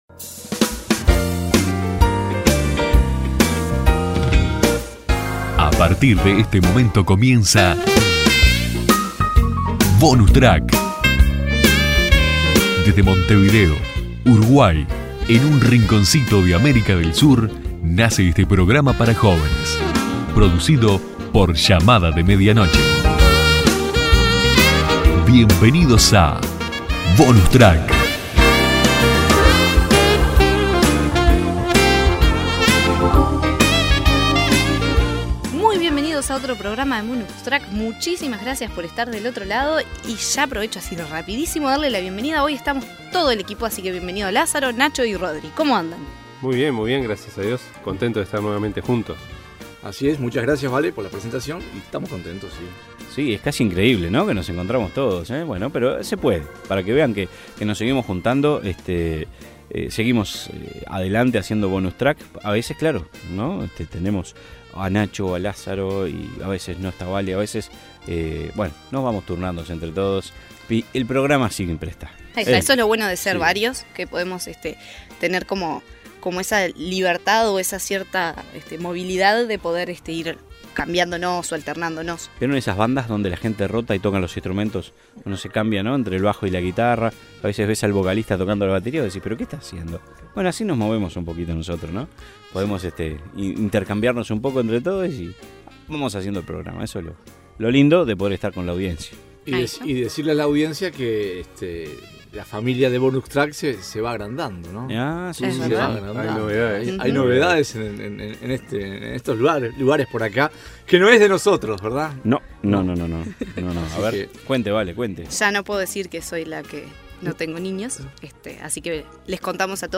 Te invitamos a reflexionar con nosotros y a escuchar una conversación que nos edifica a todos.
Bonus Track, te invita a que te prendas en sintonía durante 28 minutos para compartir un poco de todo: opiniones, invitados, un buen tema de conversación y la buena música, que no debe faltar.